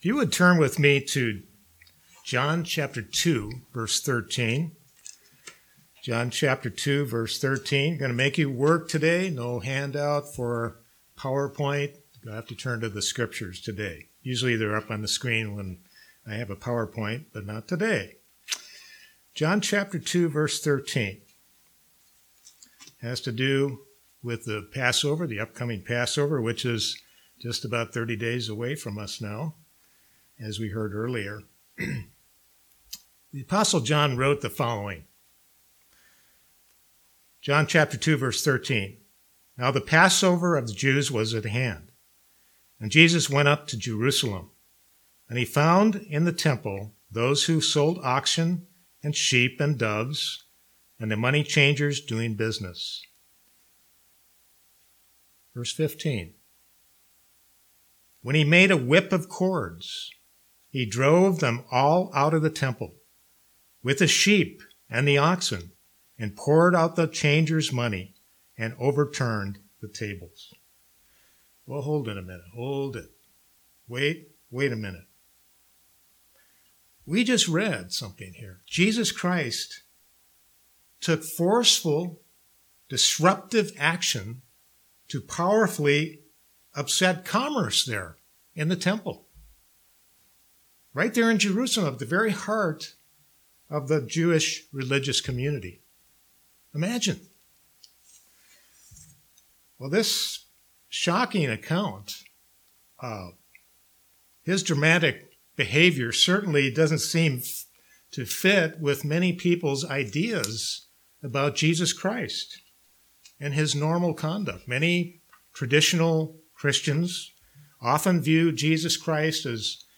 Sermons
Given in Kingsport, TN Knoxville, TN London, KY